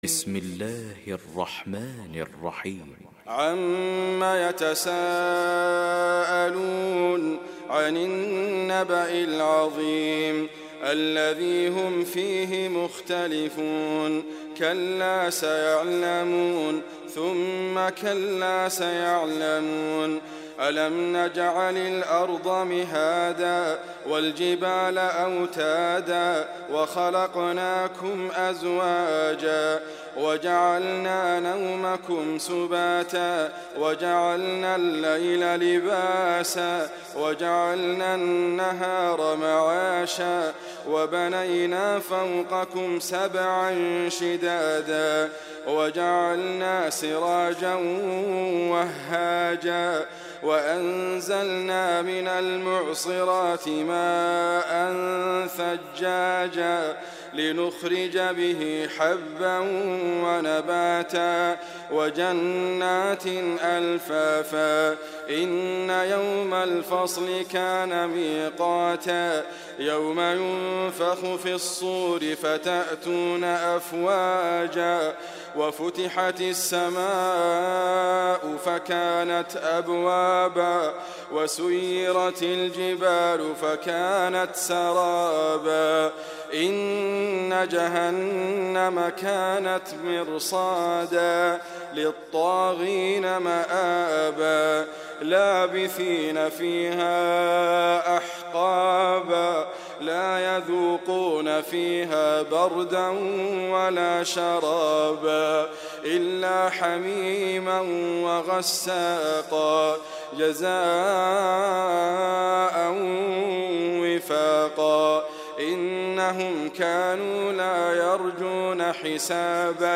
القران الكريم -> ادريس ابكر -> النبأ